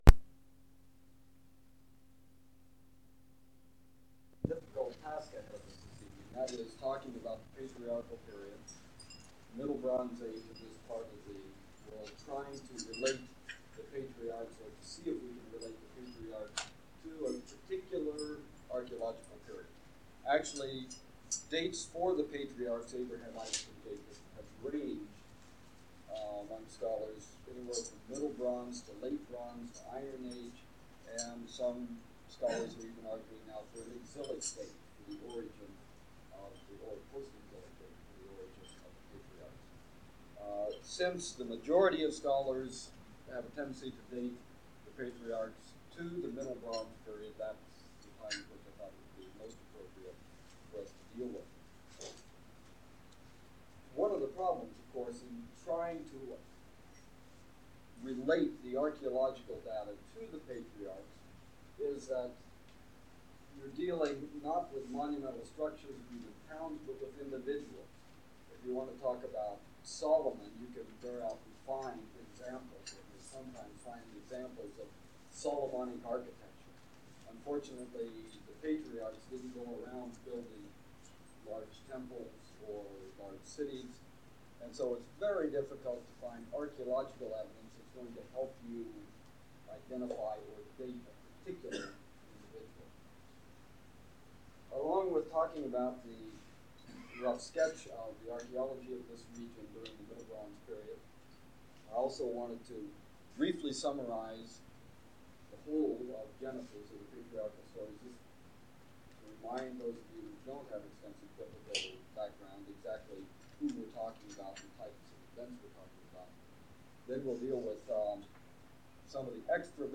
Archaeology of Jordan and Biblical History - Lecture 9